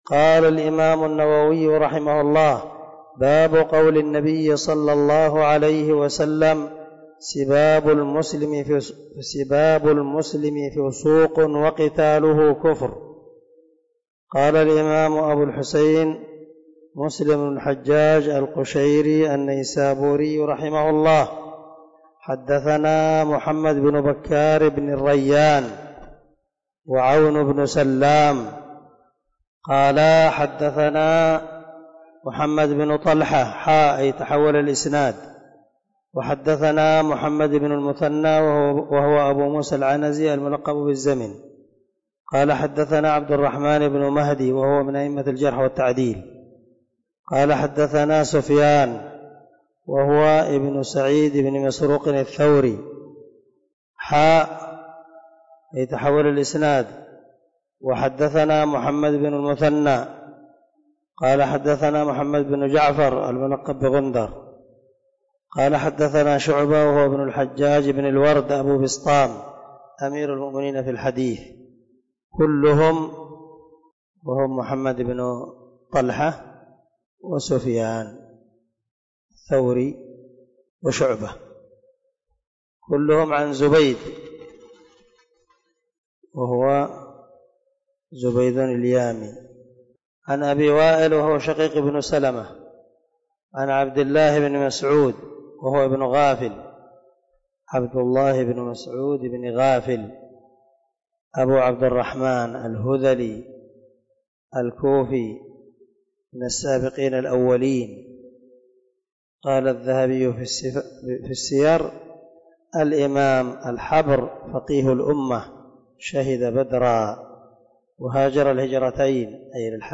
045الدرس 44 من شرح كتاب الإيمان حديث رقم ( 64 ) من صحيح مسلم
دار الحديث- المَحاوِلة- الصبيحة.